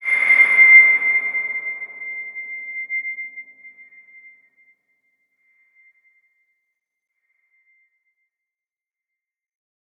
X_BasicBells-C5-mf.wav